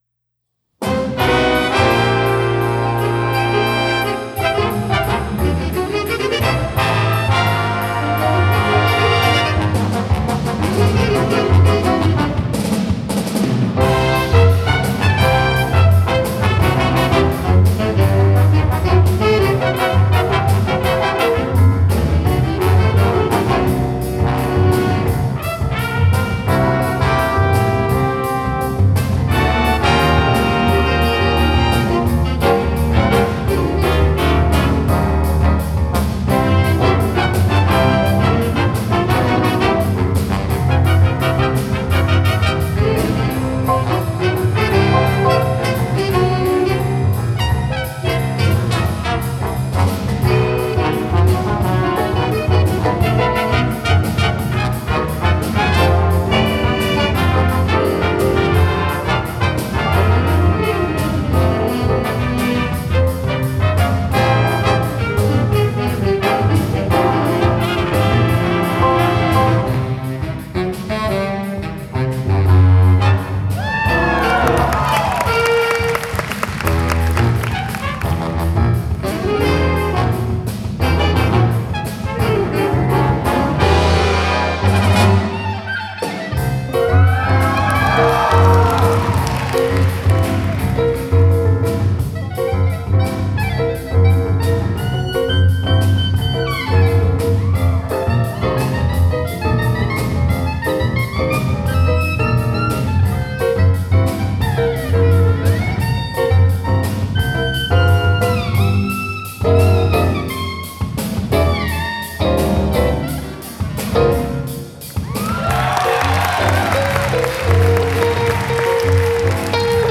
Spring Concert 2024 - Vandegrift High School
Jazz Band - The Simpsons -